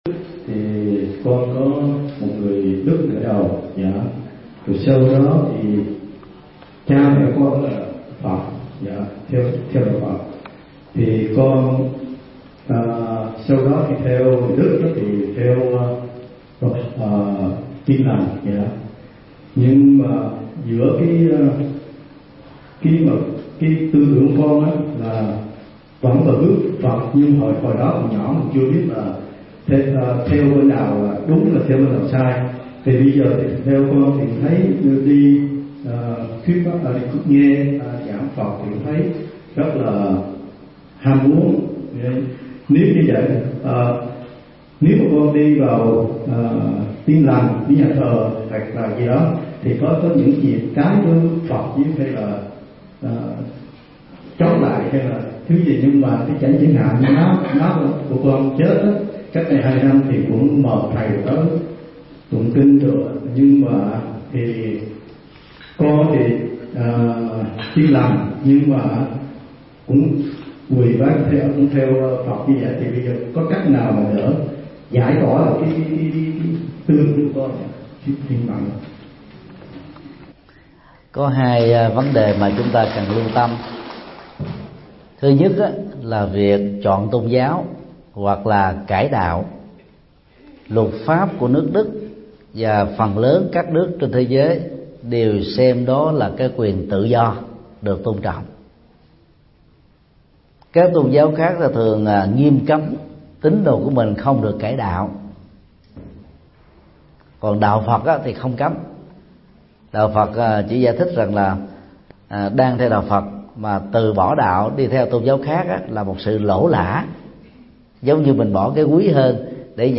Nghe mp3 Vấn đáp Hướng dẫn lựa chọn tôn giáo